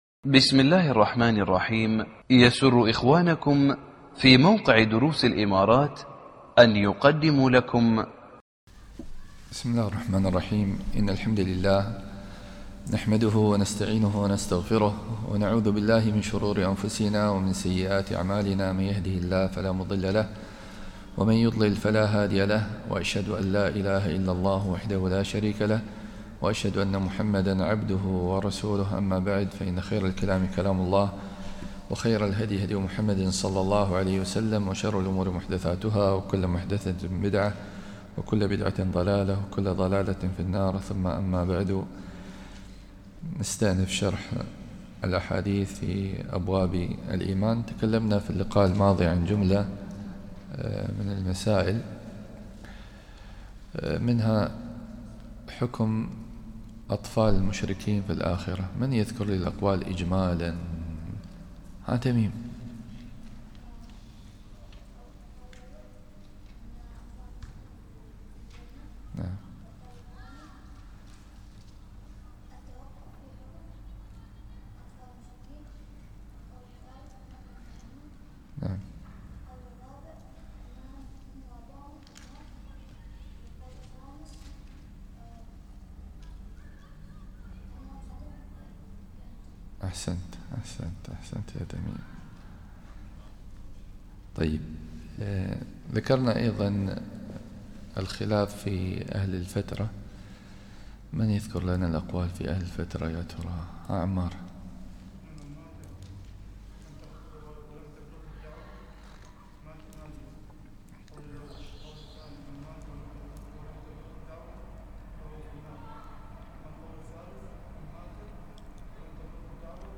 الدرس 3